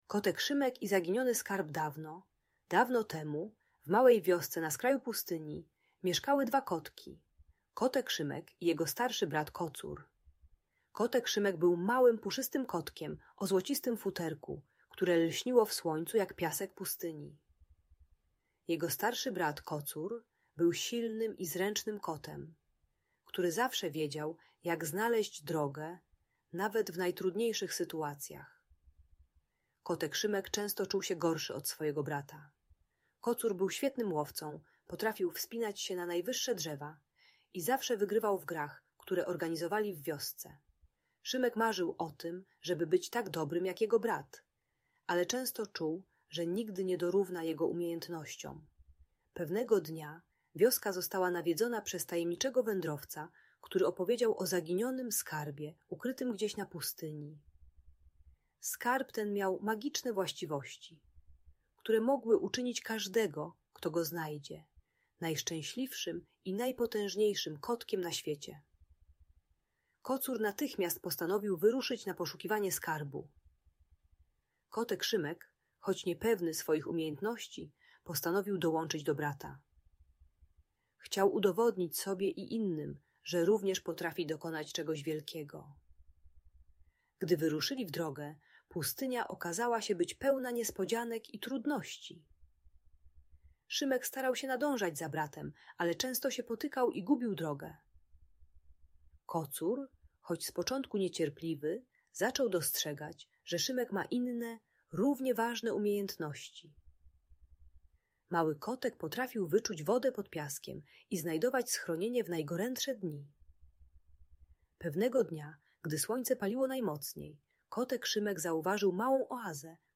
Opowieść o Kocie Szymku i Zaginionym Skarbie - Audiobajka